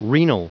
Prononciation du mot renal en anglais (fichier audio)
renal.wav